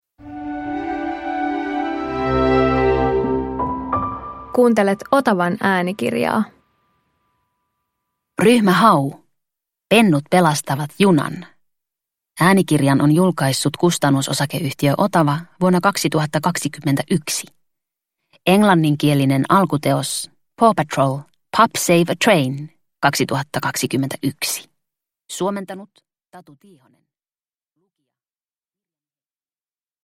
Ryhmä Hau - Pennut pelastavat junan – Ljudbok
Ryhmä Hau rientää apuun jännittävässä äänikirjassa